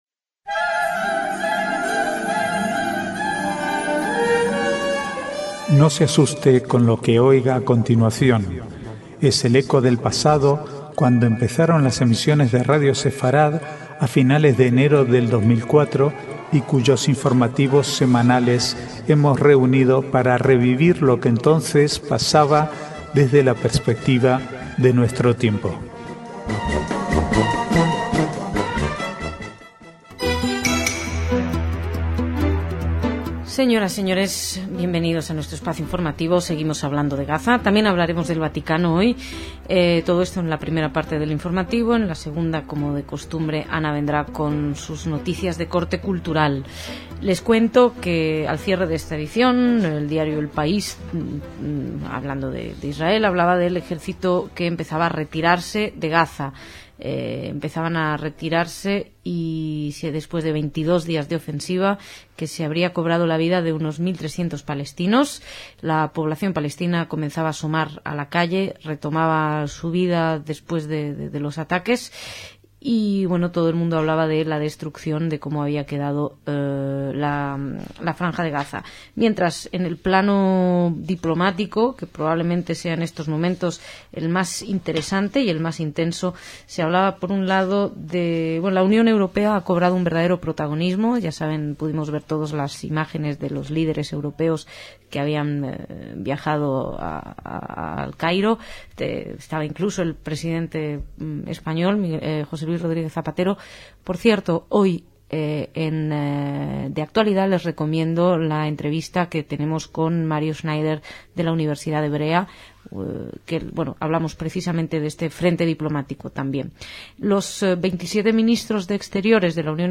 Archivo de noticias del 20 al 22/1/2009